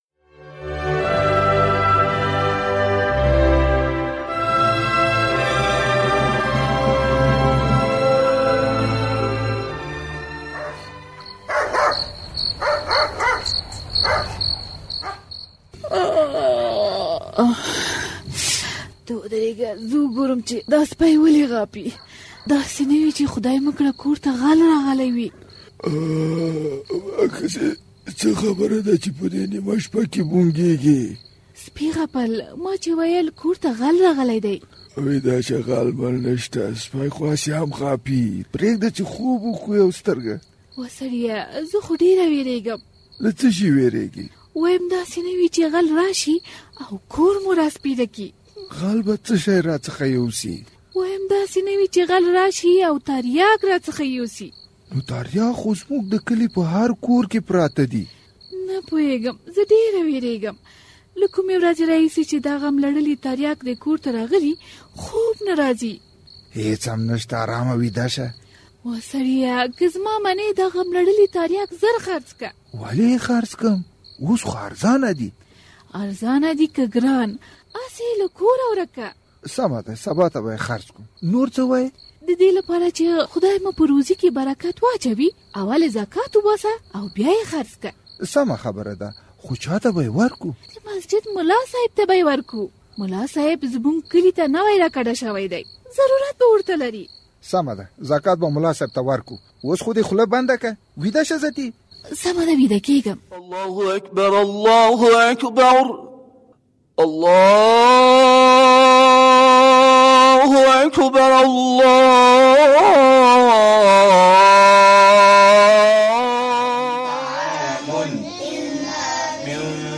د زهرو کاروان ډرامه